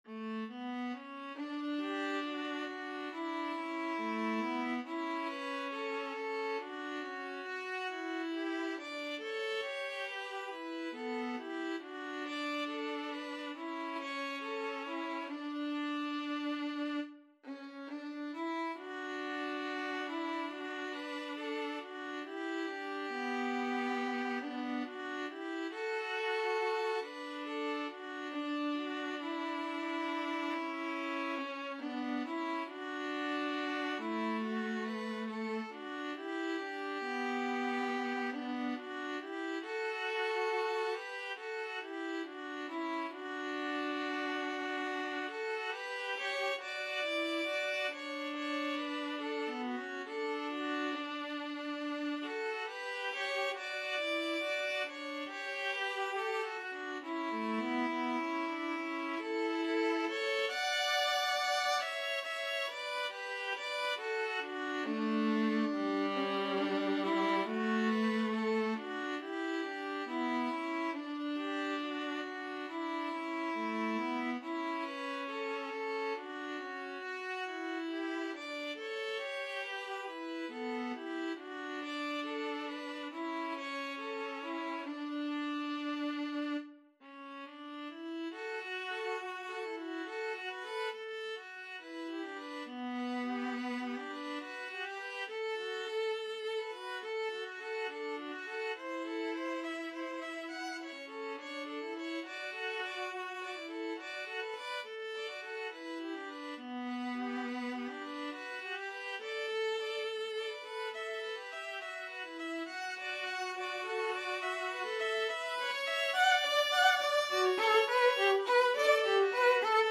4/4 (View more 4/4 Music)
Espressivo = c. 69
Classical (View more Classical Violin-Viola Duet Music)